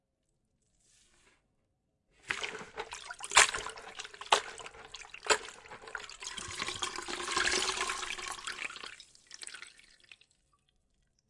家政服务 " 浸泡抹布
描述：蘸一块抹布